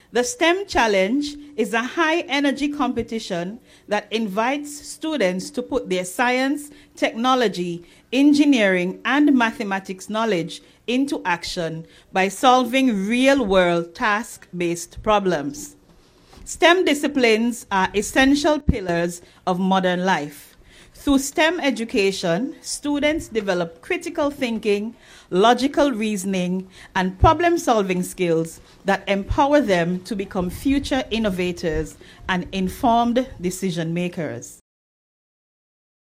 Announcer